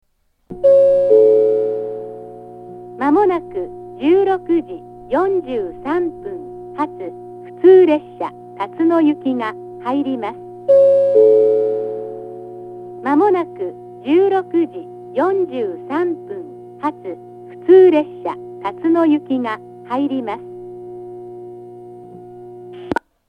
２番線上り接近予告放送 16:43発普通辰野行の放送です。